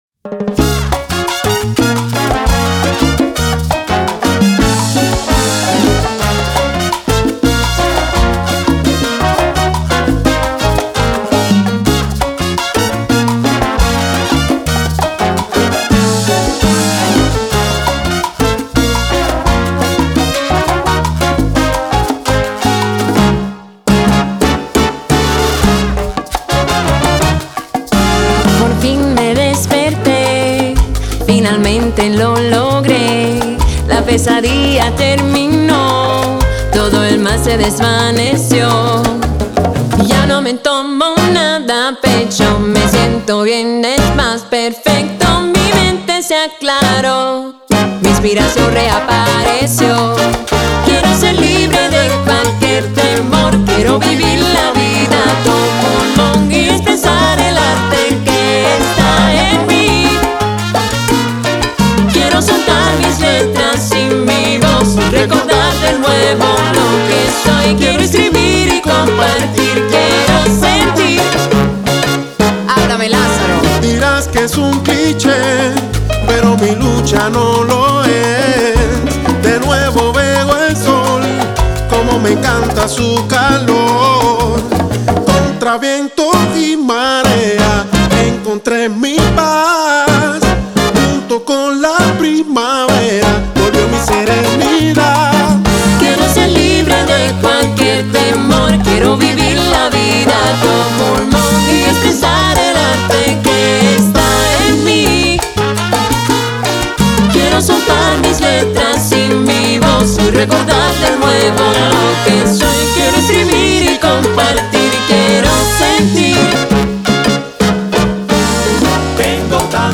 fusiona la música balcánica y caribeña